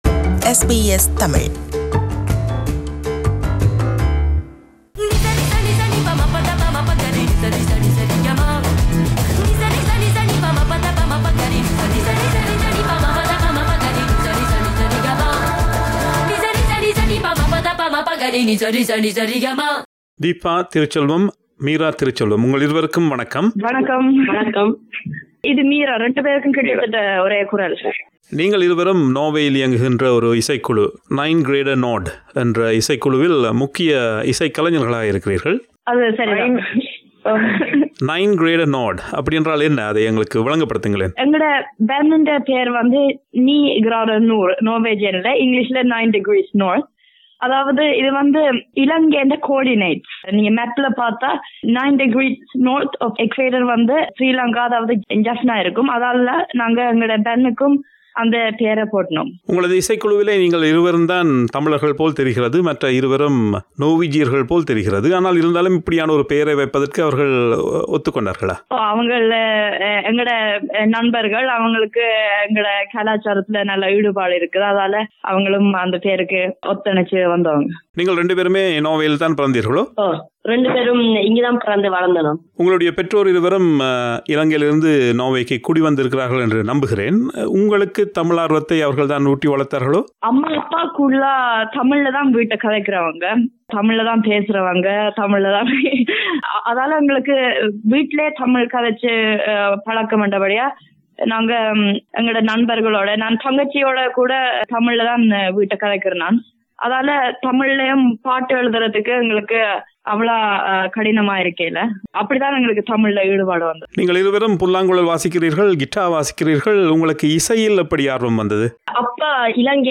அவர்களை நேர்கண்டு உரையாடுகிறார்